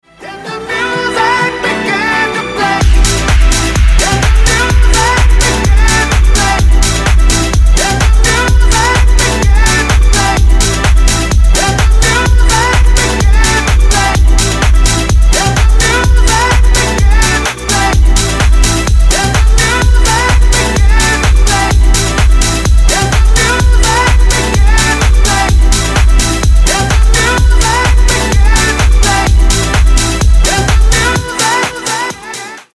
• Качество: 320, Stereo
мужской голос
громкие
зажигательные
диско
быстрые
house
Зажигательный танцевальный рингтон